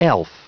Prononciation du mot elf en anglais (fichier audio)
Prononciation du mot : elf